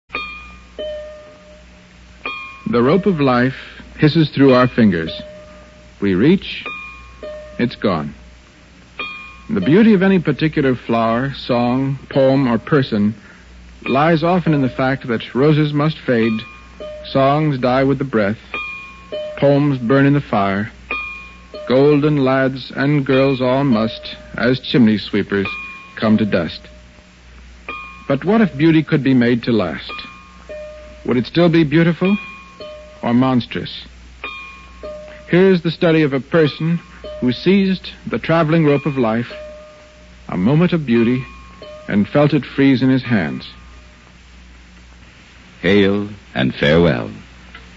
First broadcast on CBS Radio, Feb 17, 1956
He wrote and read a short introduction to each one, using the metaphor of life as a coiled rope.
here to hear Bradbury's introduction to "Hail and Farewell".